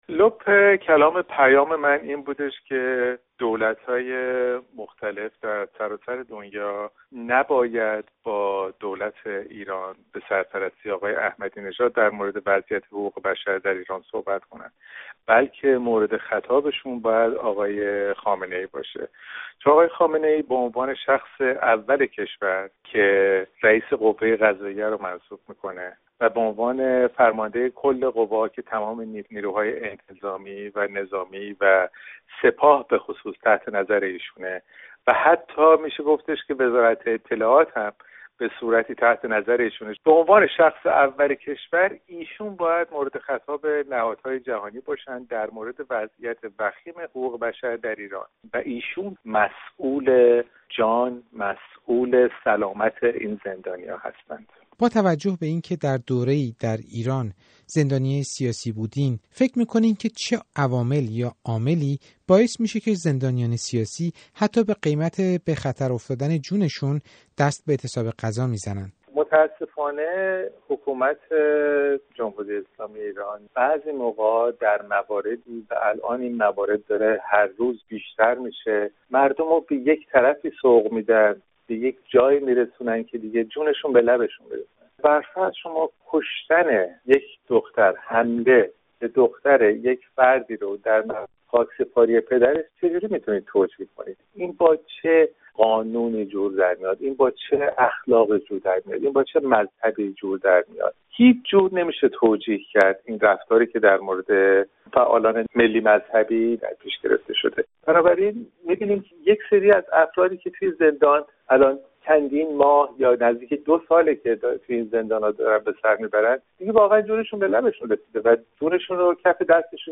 گفت و گوی رادیو فردا با مازیار بهاری، روزنامه نگار، درباره اعتصاب غذای زندانیان سیاسی در اوین